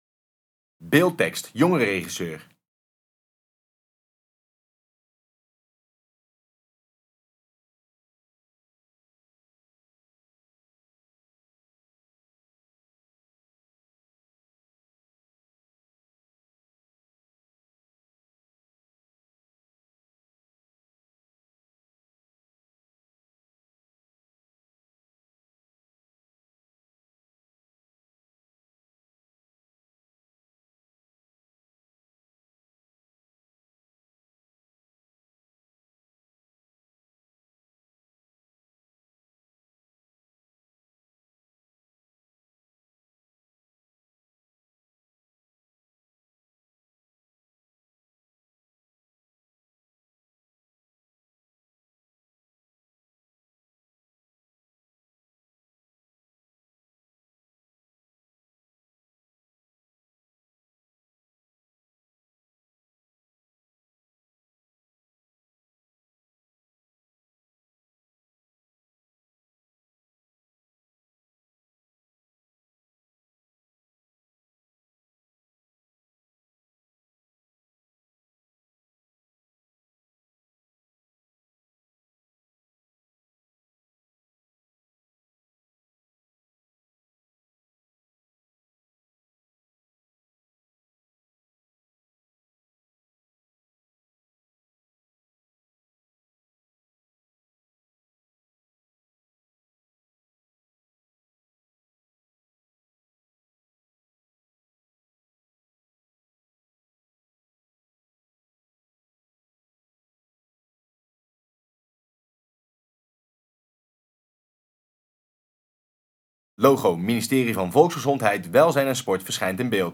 In onderstaande video’s vertellen een aantal leden van het jongerenpanel over de problemen waar dak- en thuisloze jongeren tegenaan lopen én geven ze hun kijk op hoe deze problemen opgelost kunnen worden.